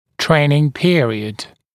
[‘treɪnɪŋ ‘pɪərɪəd][‘трэйнин ‘пиэриэд]пробный период, тренировочный этап (напр. о функц. аппаратах)